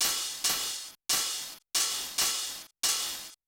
RIDE HITS -L.wav